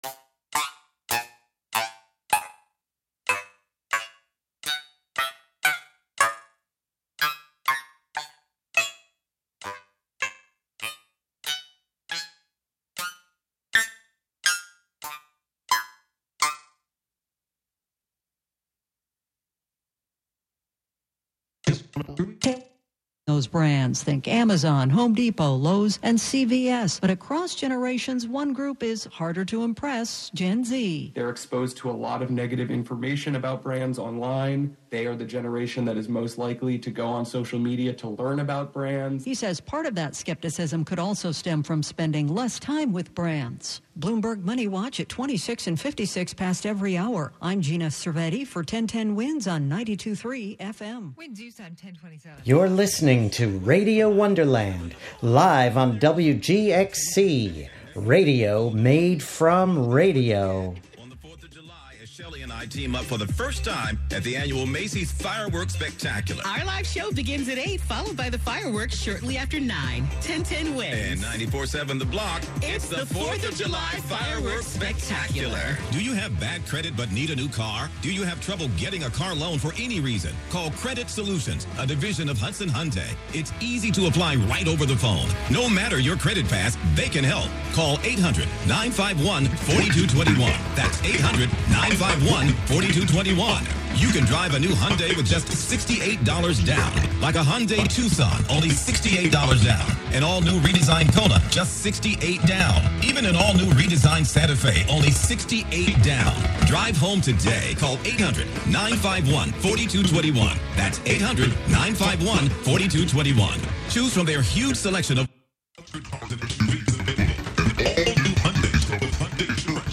11am Live from Brooklyn, New York
making instant techno 90 percent of the time